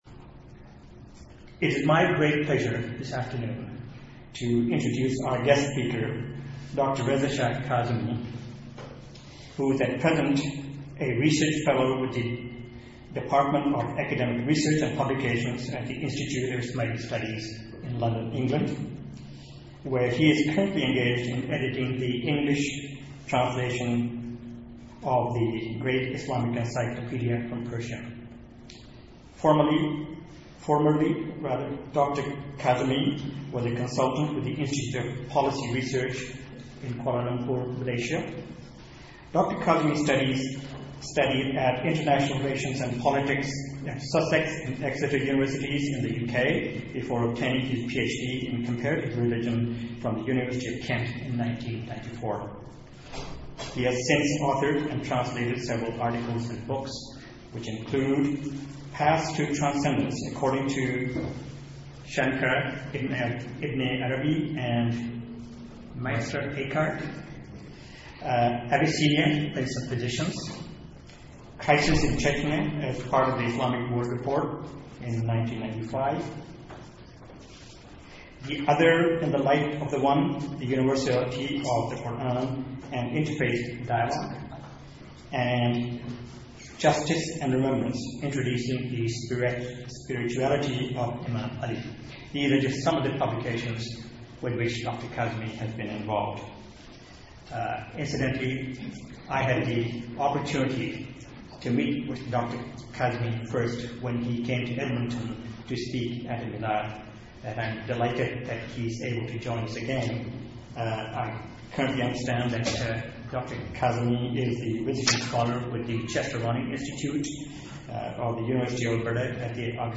Audio Lecture: The Qur’anic Perspective on Tolerance and Pluralism